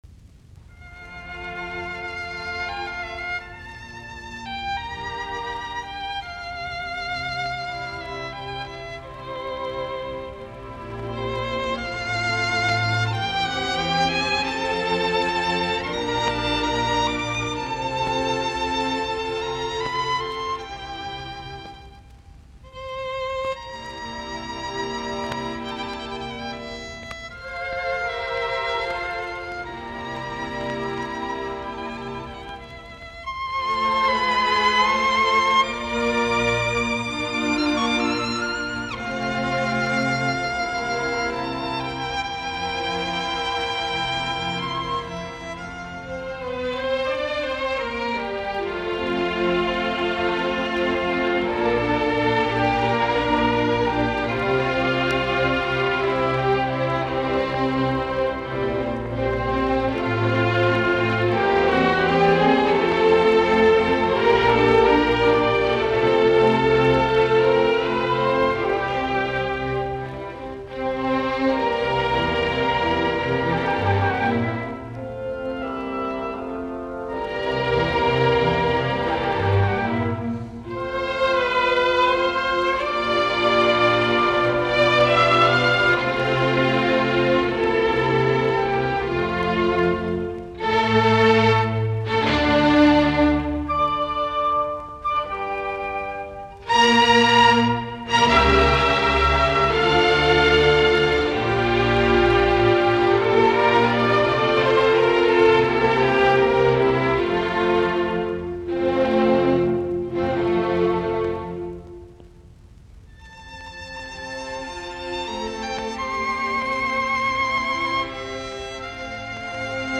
musiikkiäänite
Soitinnus: Viulu, ork.